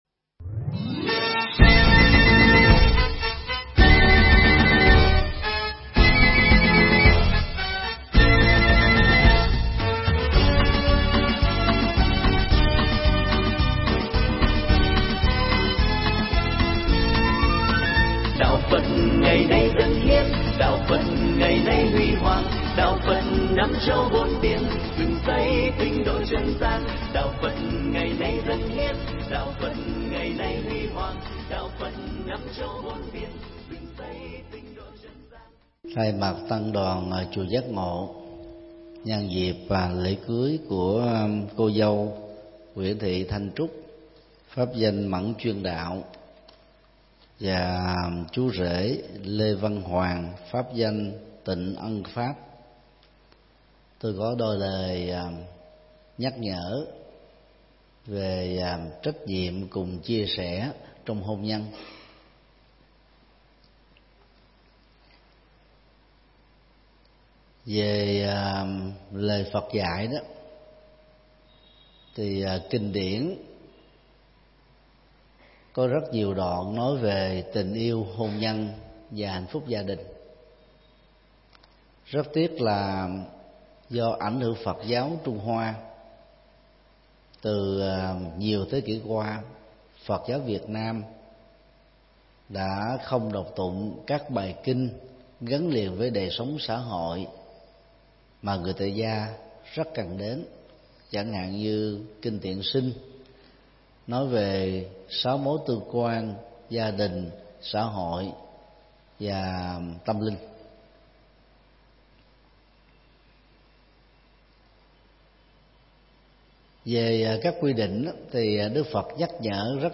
Mp3 Thuyết Giảng Trách nhiệm cùng chia sẻ trong hôn nhân P2 - Thượng Tọa Thích Nhật Từ giảng tại chùa Giác Ngộ, ngày 8 tháng 11 năm 2018